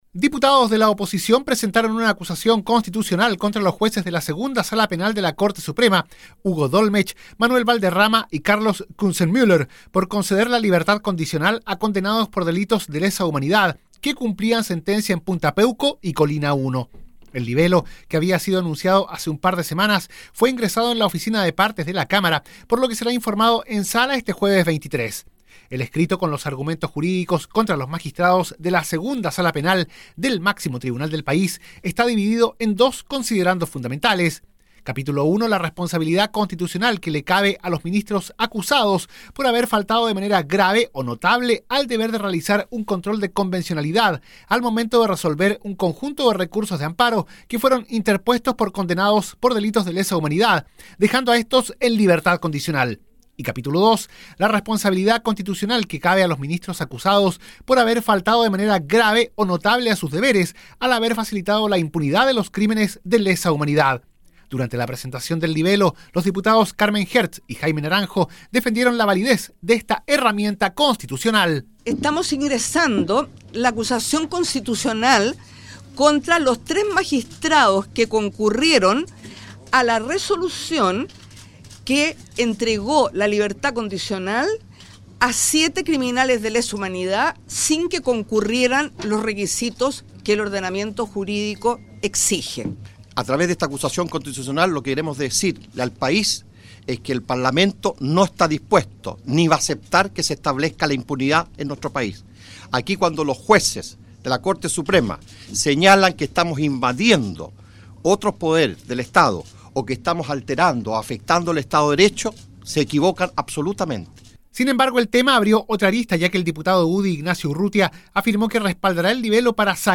Así reportó el hecho la radio de la Cámara de Diputados: